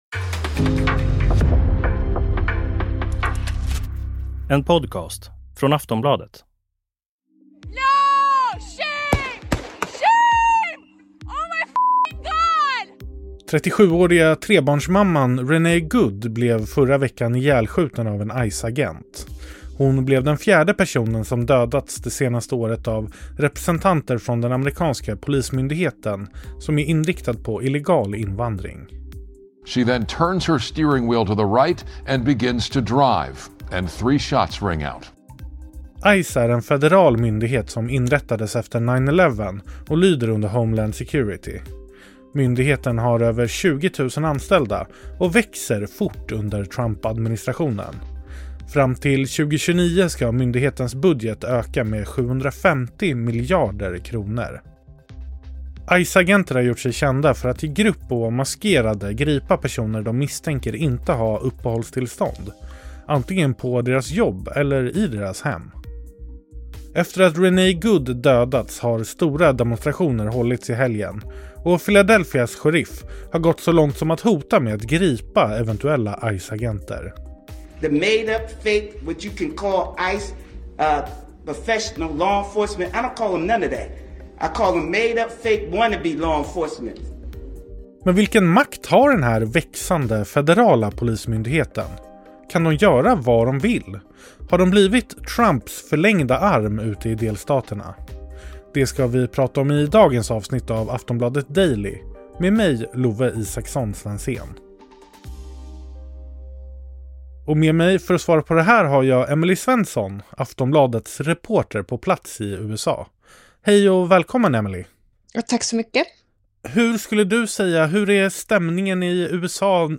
Klipp från: New York Times, Forbes, KTLA 5